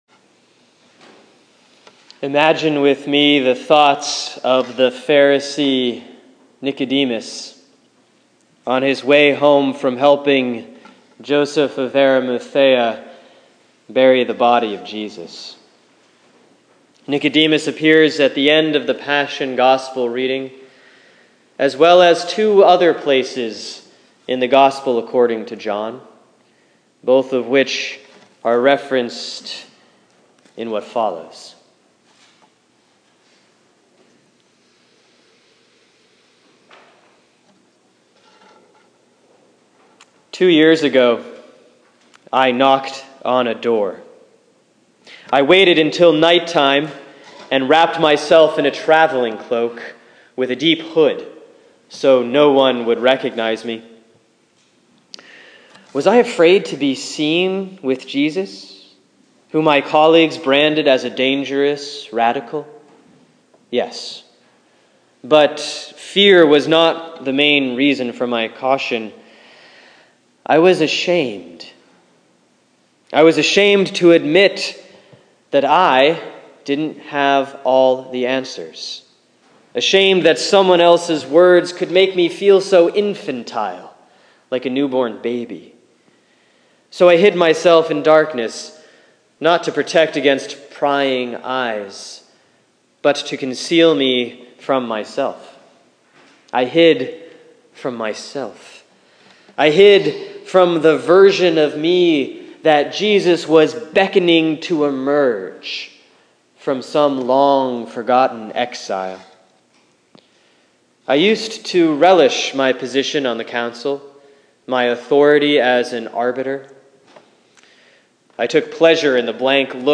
A Good Friday Meditation; John 19:31-42